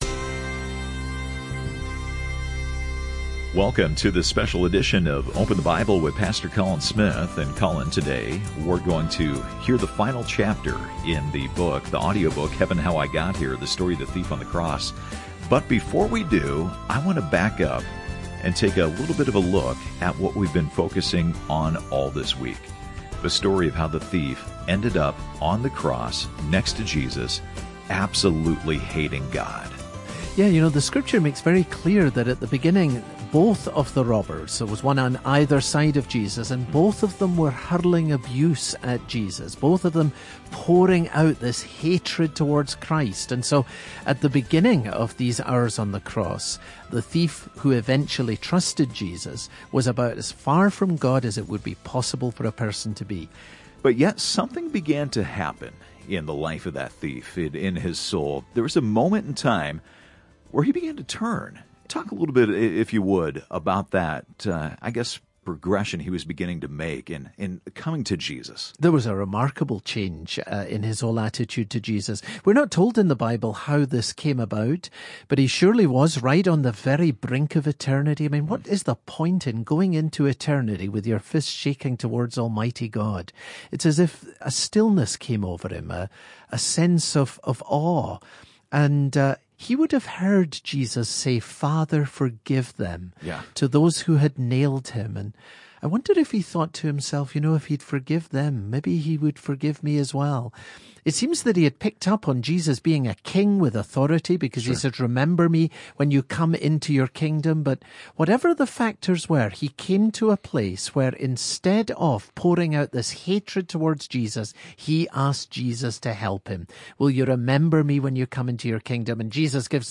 This radio broadcast features narration by actor Stephen Baldwin.